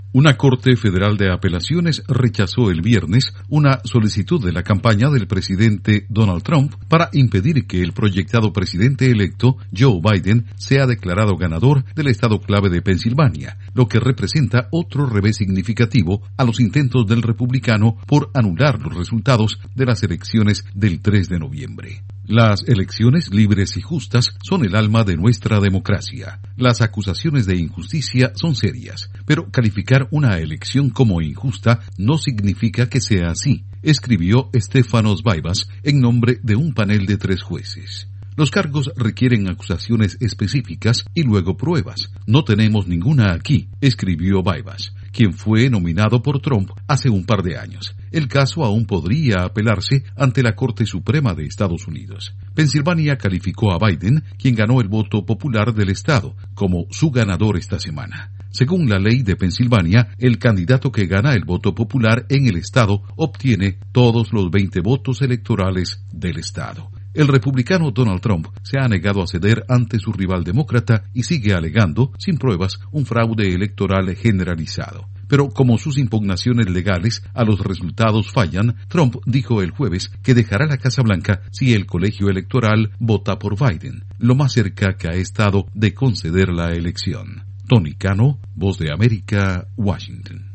Tribunal de apelaciones desestima demanda electoral de Trump en Pensilvania. Informa desde la Voz de América en Washington